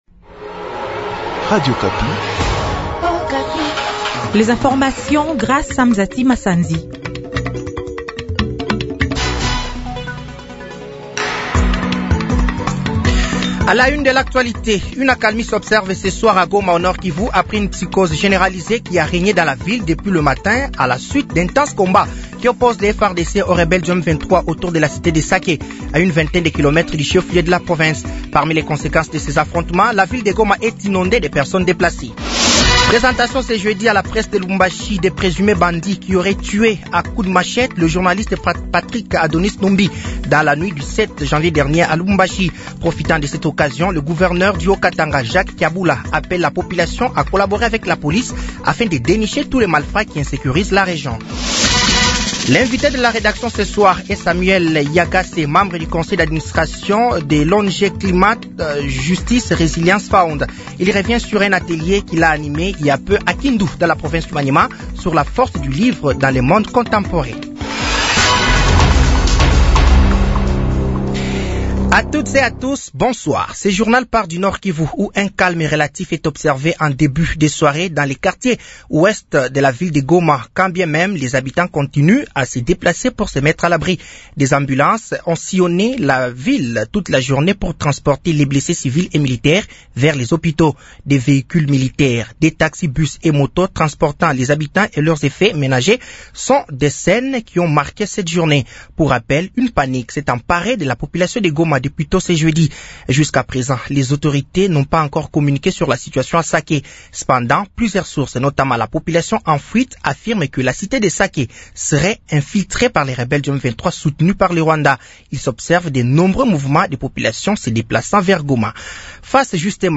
Journal français de 18h de ce jeudi 23 janvier 2025